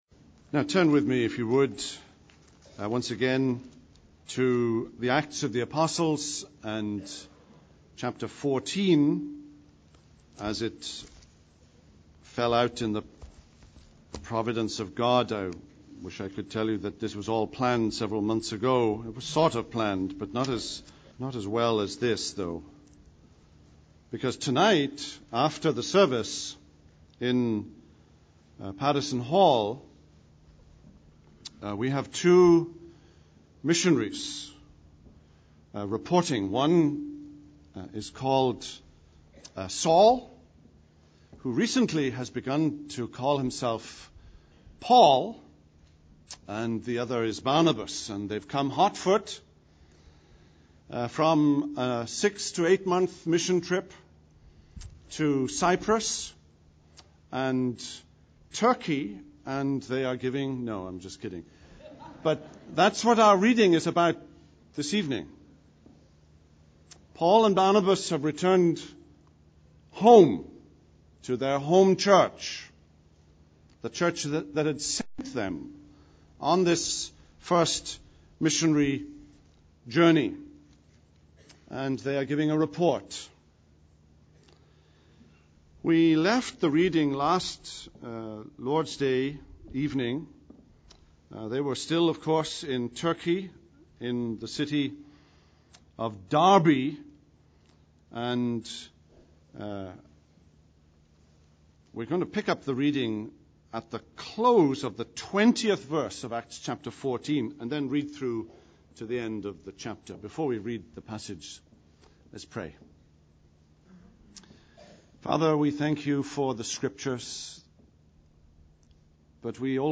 Discover a wealth of resources at First Presbyterian Church of Jackson, Mississippi.